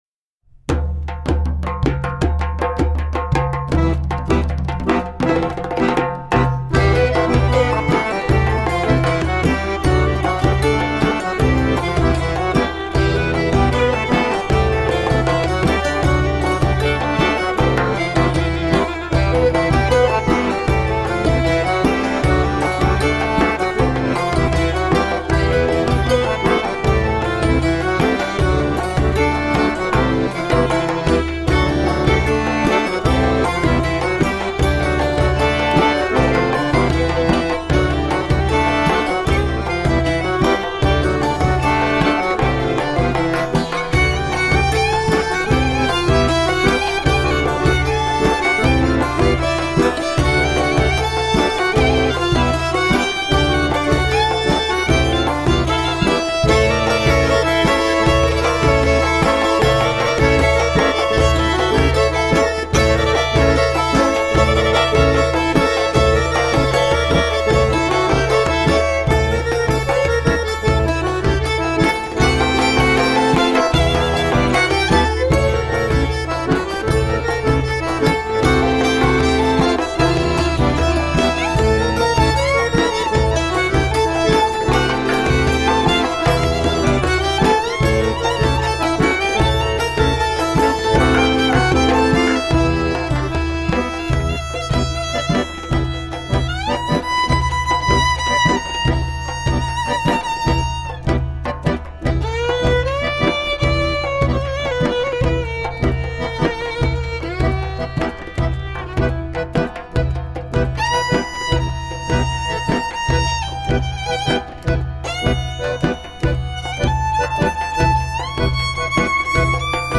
Eastern and western european folk music..